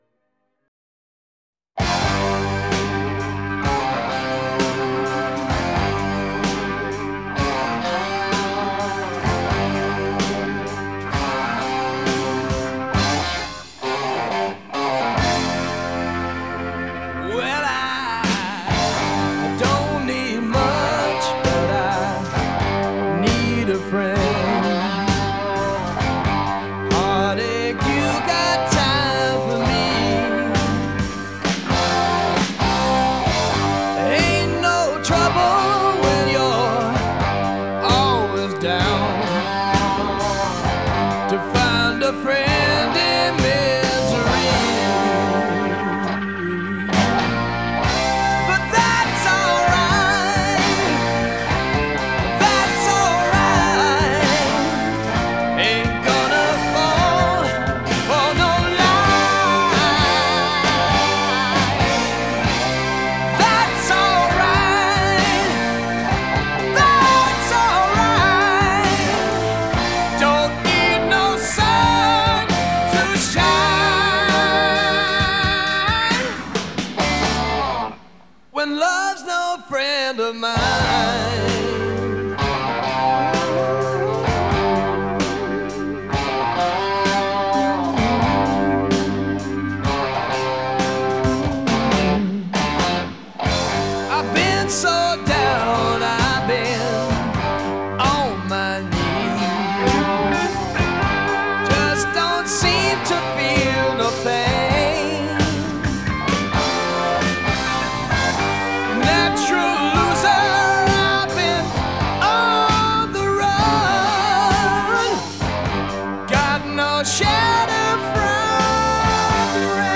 Жанр: Хард-рок